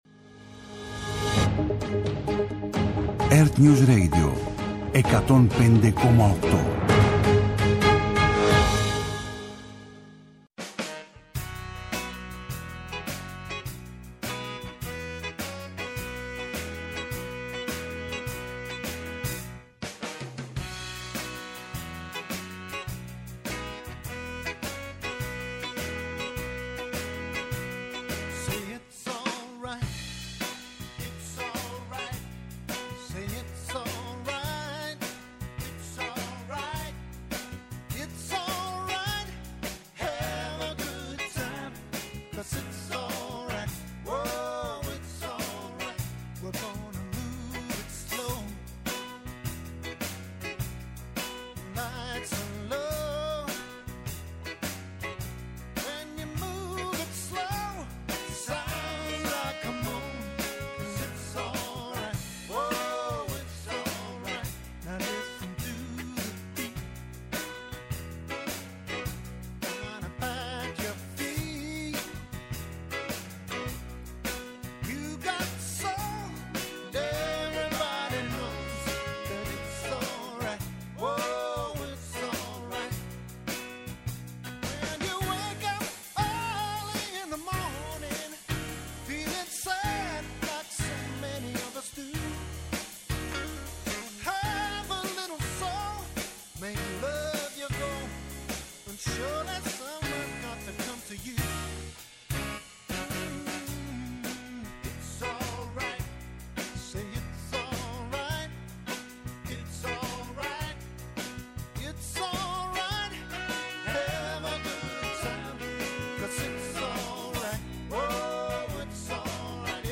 -Ο Δήμαρχος Βόρειας Κέρκυρας, Γιώργος Μαχειμάρης, για τα προβλήματα από την κακοκαιρία